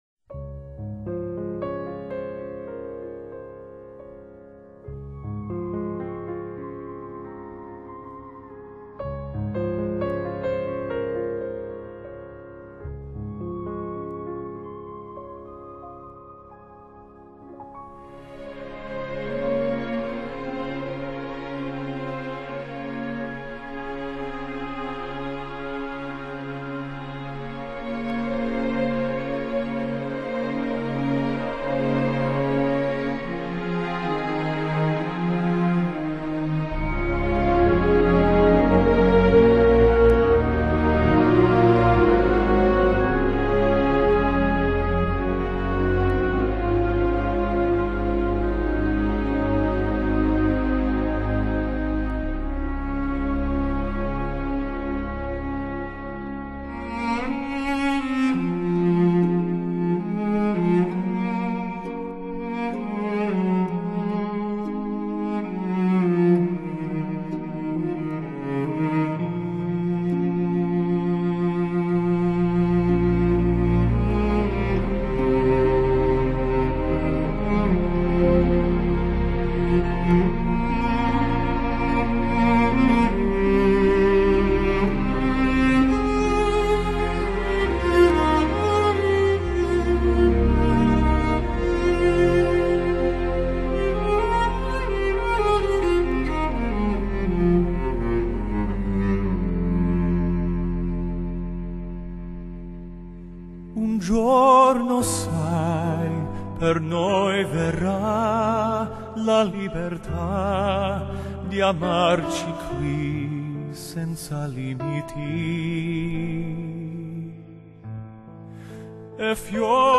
他是一位年輕的男中音。
他的音色渾厚、有感情，有人說他的歌聲比 Andrea Bocelli 多了一份更能感動他人的特質。